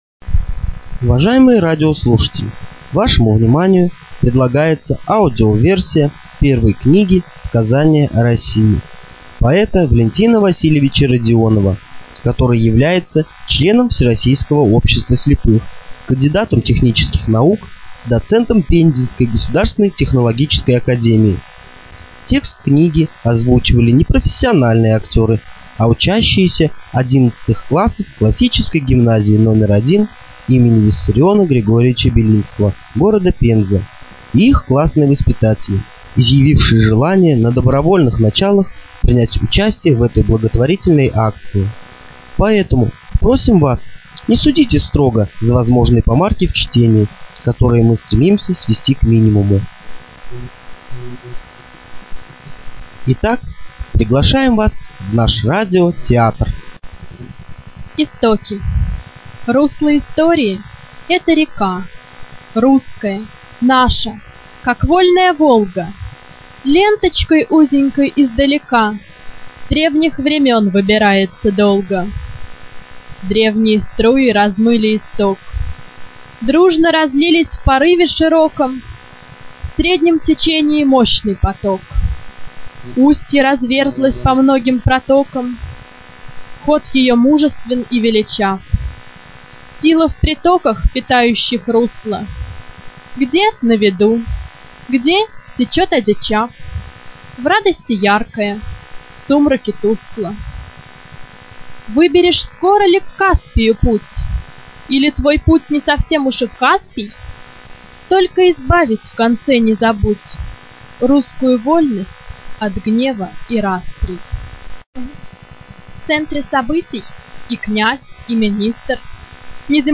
Студия звукозаписиПензенская областная библиотека им. М.Ю. Лермонтова